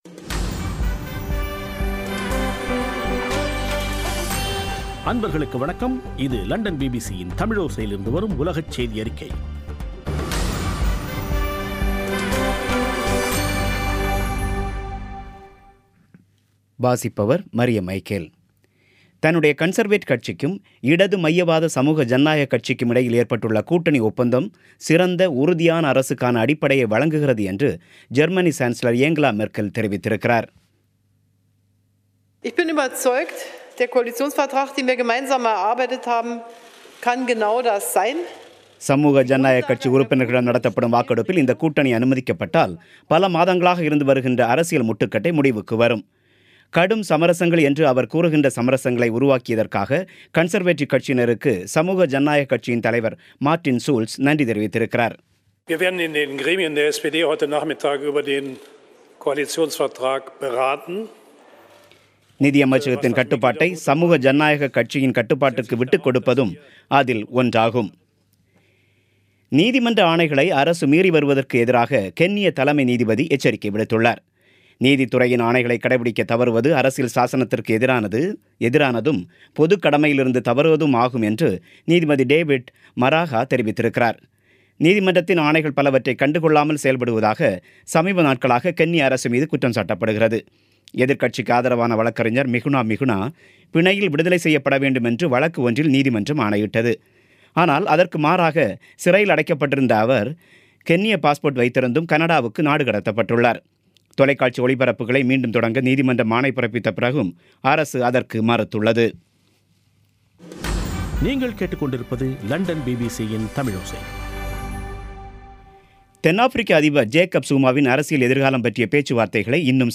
பிபிசி தமிழோசை செய்தியறிக்கை (07/02/2018)